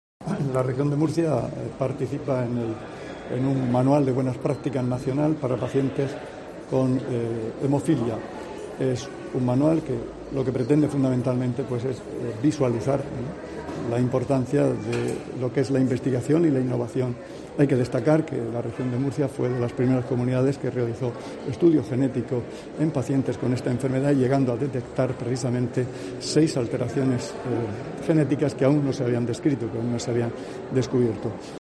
Juan José Pedreño, consejero de Salud